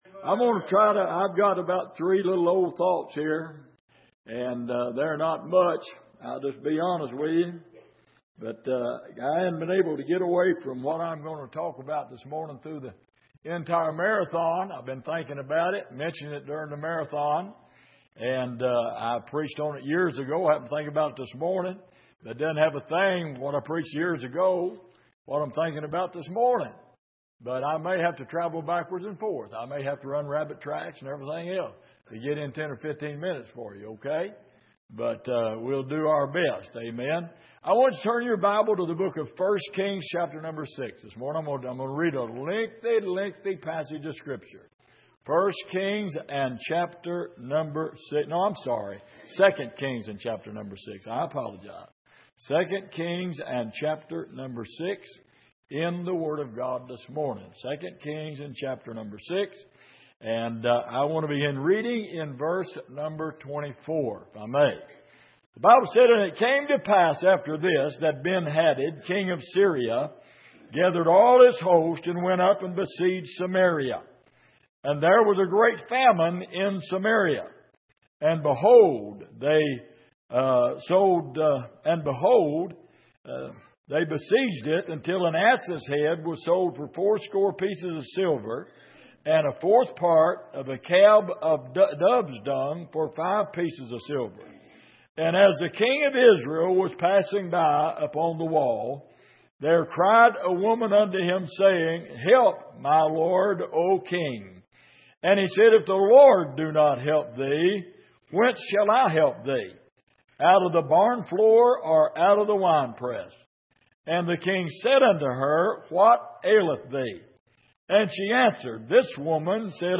Passage: 2 Kings 6:24-33 Service: Sunday Morning